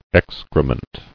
[ex·cre·ment]